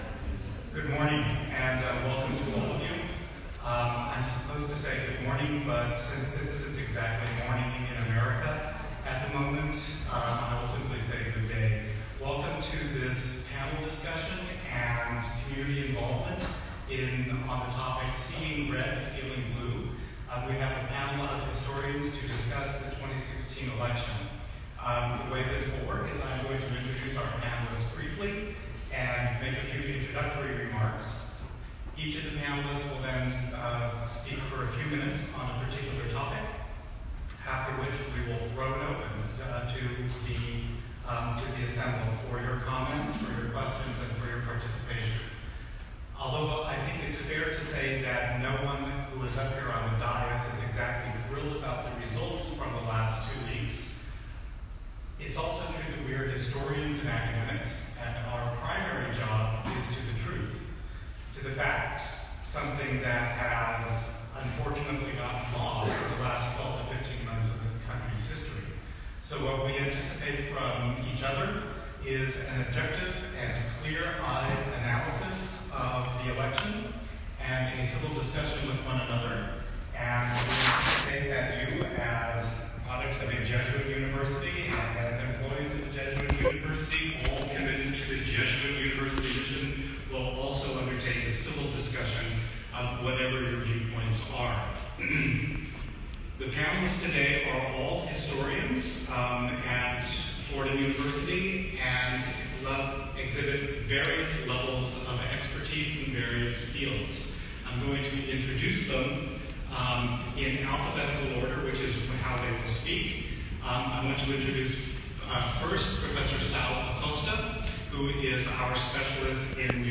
Following the 2016 election this month, a panel of historians at Fordham University discussed the results and President-Elect Trump through the lenses of different historical perspectives on November 22, 2016.